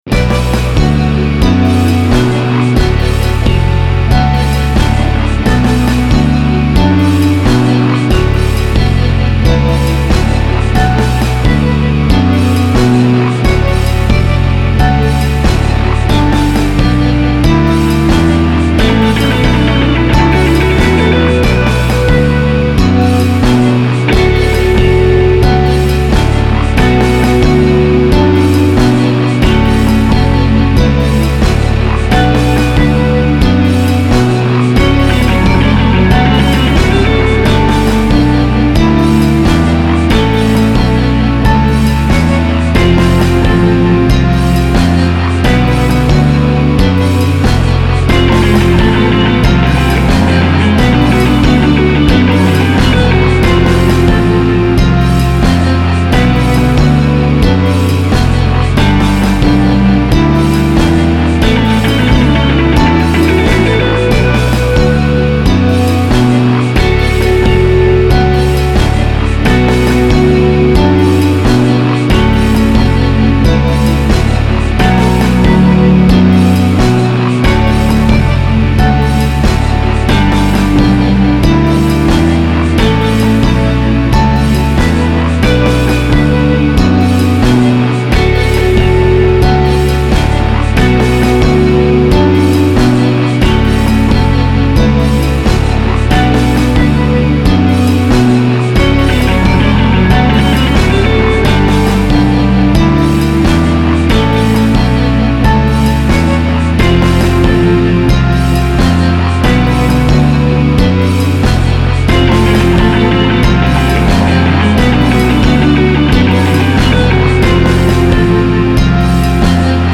After listening to the previous version of the song for a while, I decided that I like (a) "sparkled" shakers with guiro better than (b) "sparkled" cowbells with guiro, so I changed the "sparkled" Latin percussion to do a shuffling back-and-forth accenting motion that enhances the dreamy aspect of the rhythm pattern in a mesmerizing way . . .
The third lead guitar phrase spans two measures, and it is played on tempo, where it mostly is doing what I suppose is a set of Heavy Metal or Gothic arpeggios or scales . . .
I also adjusted some of the volume levels for a few of the tracks, but this was done while listening with headphones, so the mix is approximate at best, really . . .
-- Basic Rhythm Section